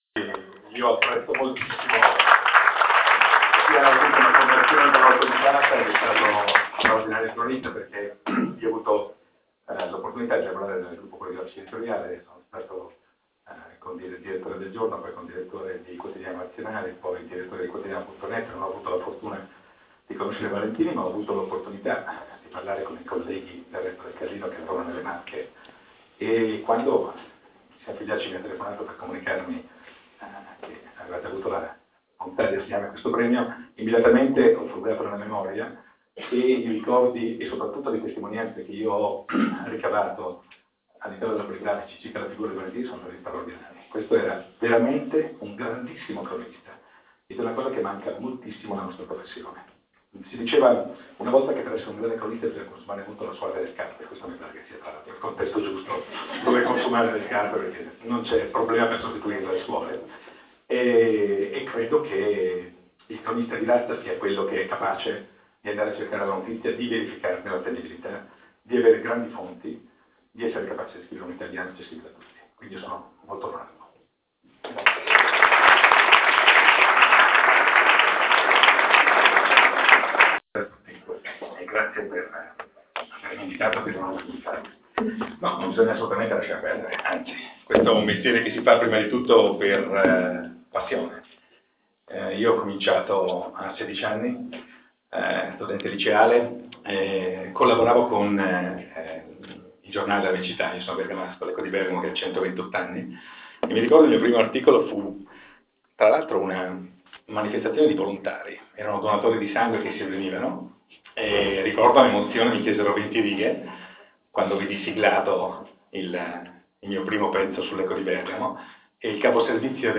Riascolta l'intervento Xavier Jacobelli (Bergamo, 29 agosto 1959) è un giornalista italiano.